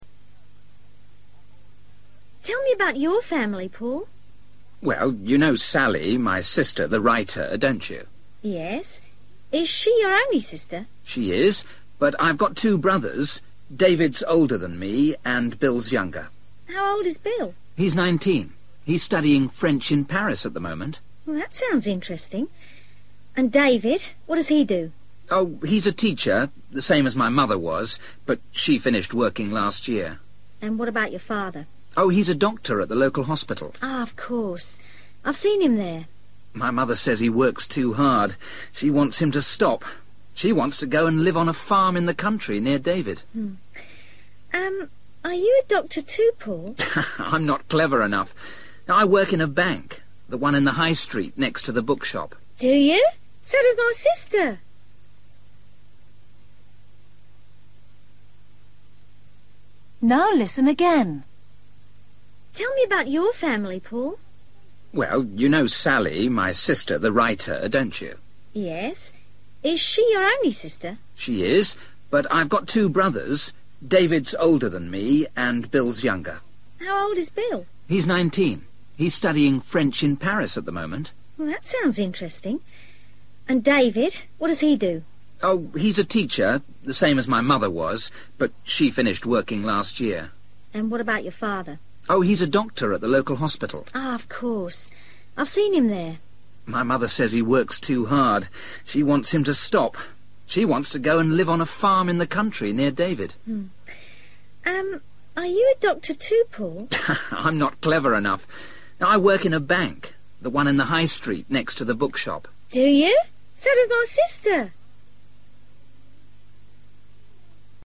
Bài tập luyện nghe tiếng Anh trình độ sơ trung cấp – Nghe cuộc trò chuyện và chọn câu trả lời đúng phần 45